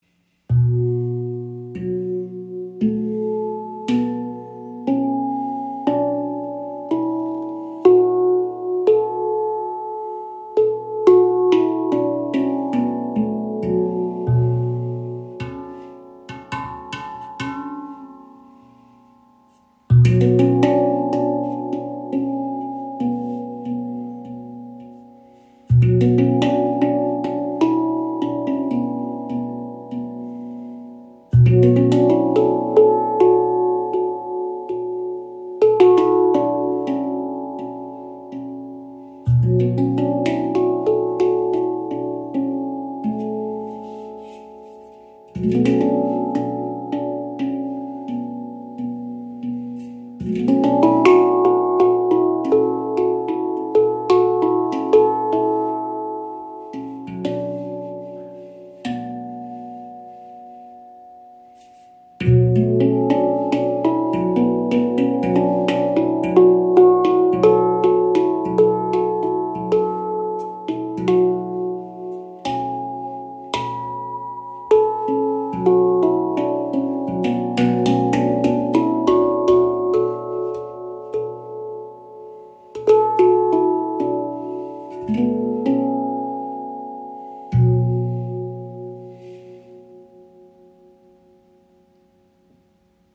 Handpan ShaktiPan | B2 Celtic | 432 Hz im Raven-Spirit WebShop • Raven Spirit
Klangbeispiel
Diese ShaktiPan aus Ember Steel in B Celtic har eine sehr beliebte harmonische Stimmung, wobei alle Klangfelder sehr gut gestimmt sind.
Edelstahl (Stainless Steel) gehört zu den oft gewählten Materialien bei Handpans, da es eine schöne Klangfarbe und eine angenehme, besonders lange Schwingung (den sogenannten Sustain) erzeugt.